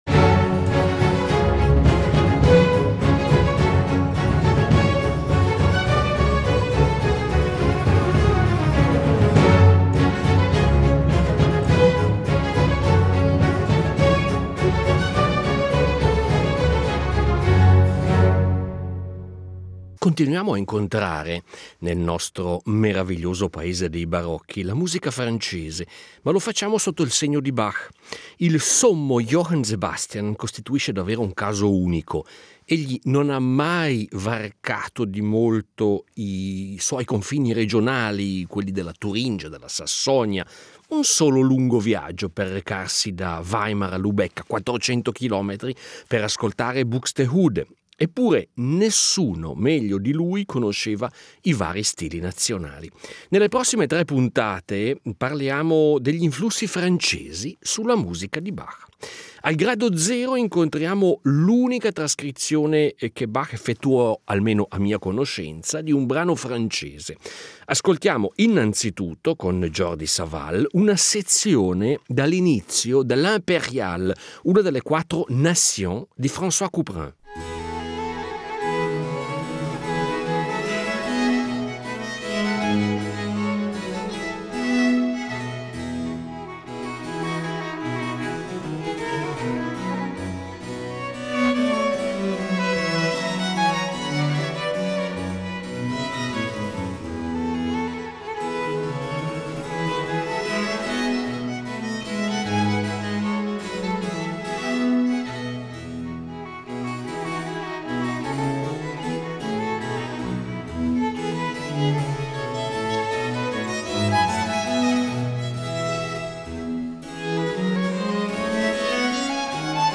Curiosando tra le numerose composizioni del sommo Johann Sebastian, rintracciamo numerose composizioni impregnate dello stile francese. In tre puntate del nostro meraviglioso “Paese dei Barocchi” ascoltiamo estratti da trascrizioni di Couperin, dall’Arte della Fuga, da Suites clavicembalistiche, brani per organo, Suites per orchestra, Variazioni Goldberg e Concerti brandeburghesi, tutti dotati del tipico condimento francese.